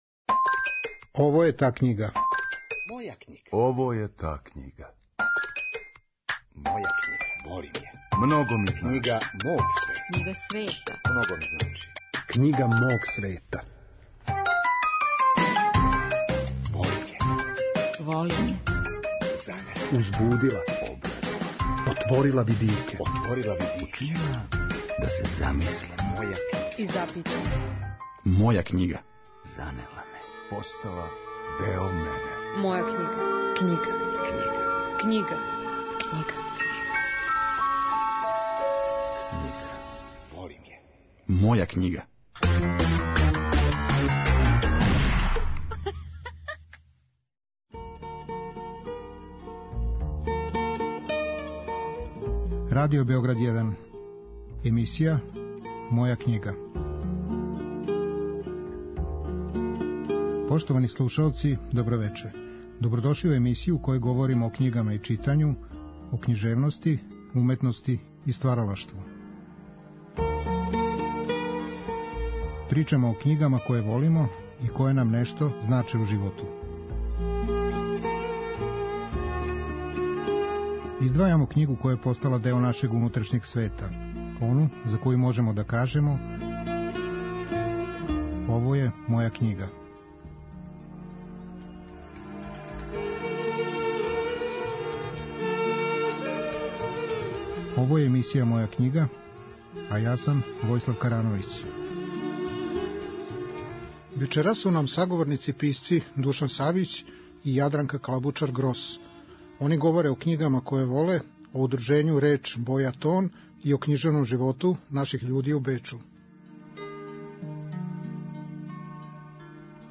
Чућемо тонске записе разговора који је са њима вођен у Бечу у мају 2022. године.